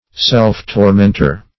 Self-tormentor \Self`-tor*ment"or\, n. One who torments himself.